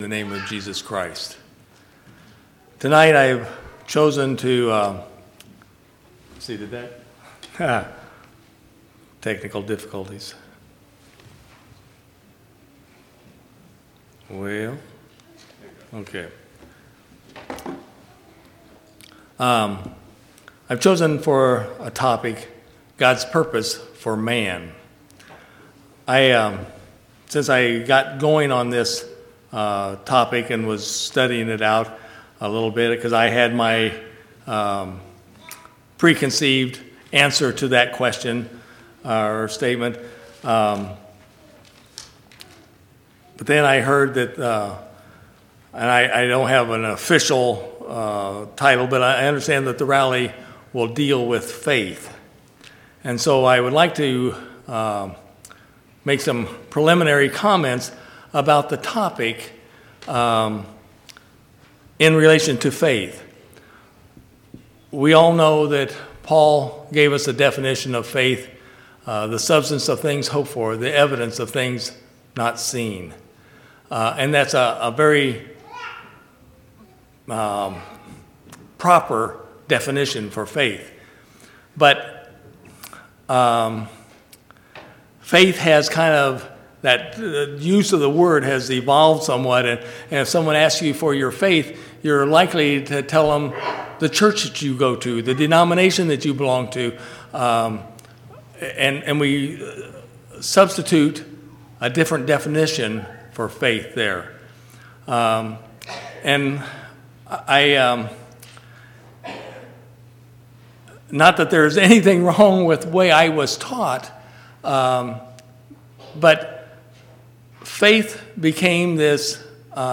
12/9/2018 Location: Temple Lot Local Event